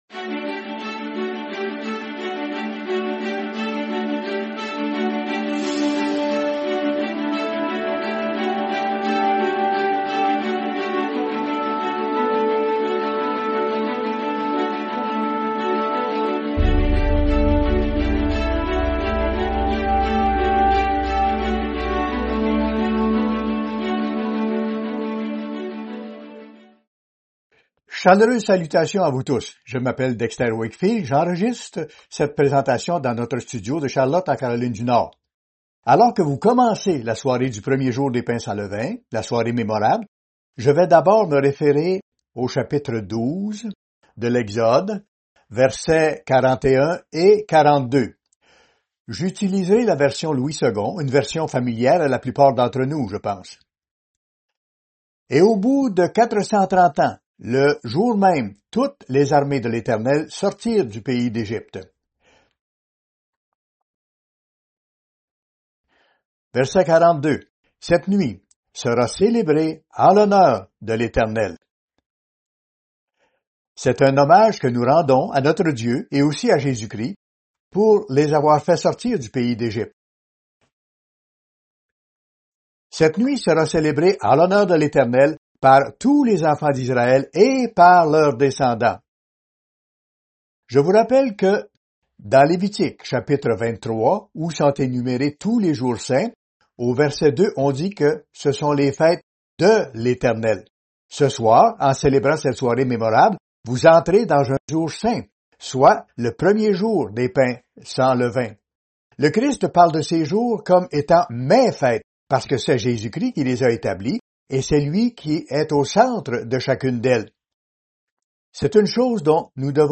Commentaire à l’occasion de la Soirée mémorable